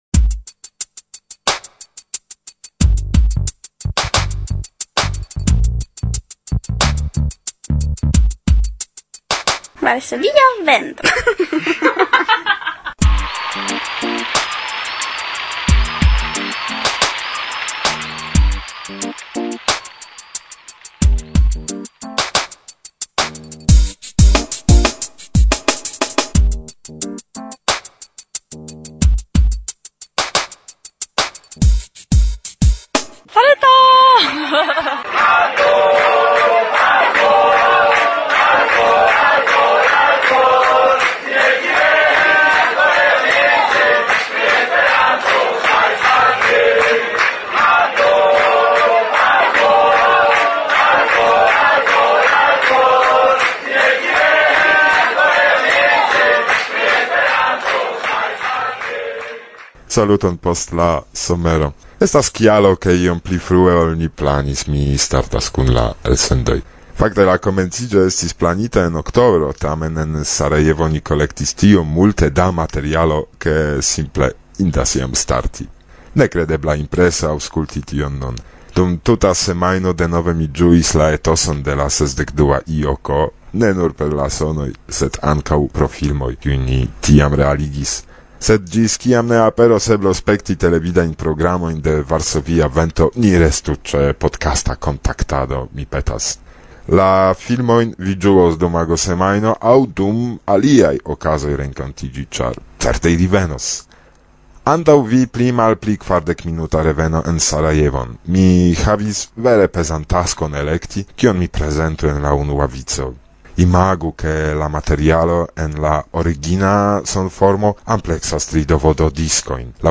La 62a IJK en Sarajevo – dum la kongreso-fermo